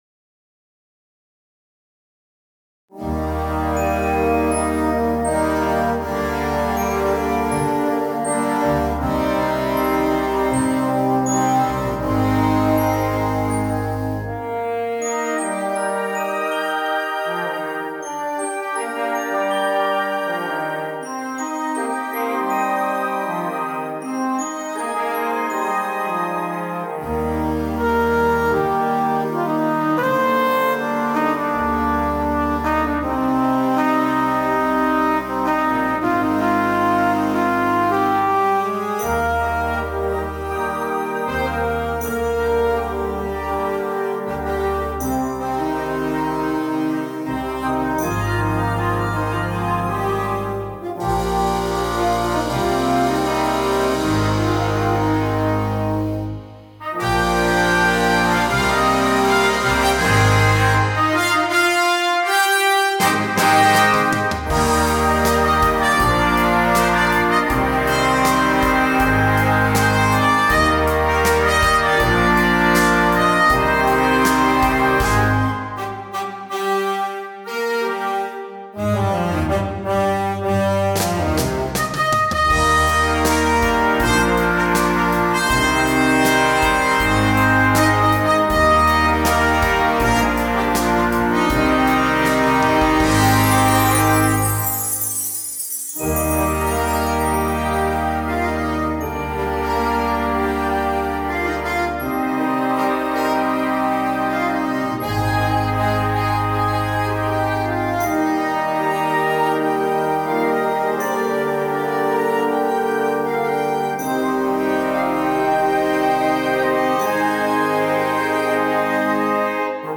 Concert Band
Piccolo
Bb Clarinets 1-2-3
Eb Alto Saxophones 1-2
Bb Trumpets 1-2-3
Tuba
Drum Set
Glockenspiel